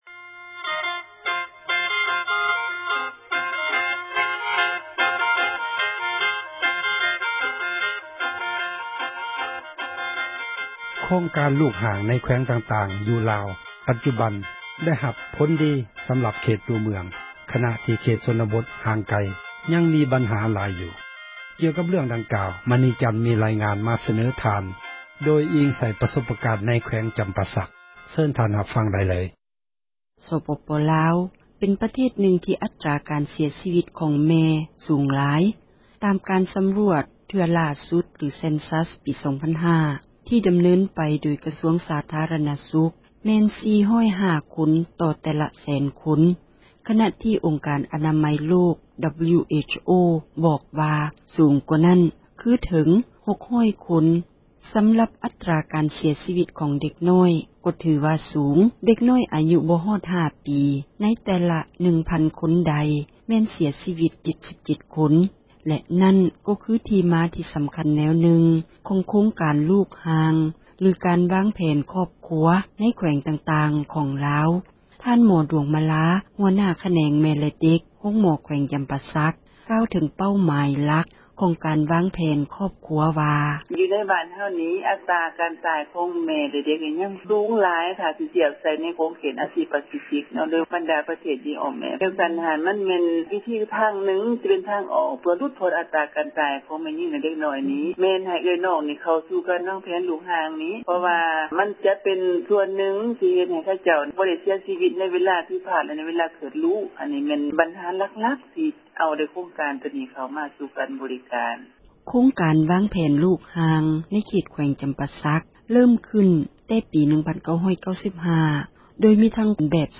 ໂຄງການລູກຫ່າງ ໄດ້ຜົນດີ ໃນເຂດຕົວເມືອງ ບັນຫາ ຍັງມີ ໃນເຂດຊົນນະບົດ — ຂ່າວລາວ ວິທຍຸເອເຊັຽເສຣີ ພາສາລາວ